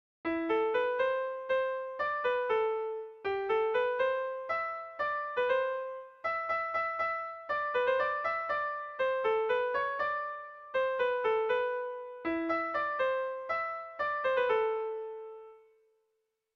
Sentimenduzkoa
A1A2BD